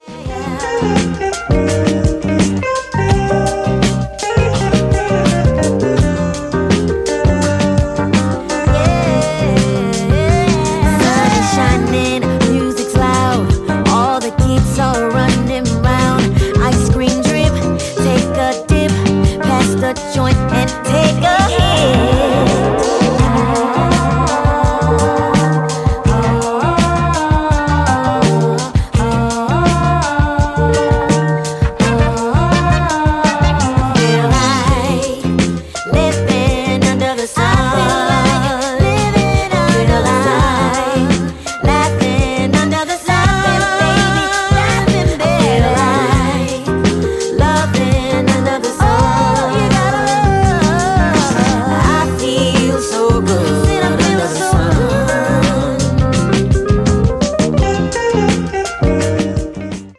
Funk / Soul / Disco